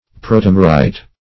Search Result for " protomerite" : The Collaborative International Dictionary of English v.0.48: Protomerite \Pro`to*mer"ite\, n. [Proto- + -mere + -ite.]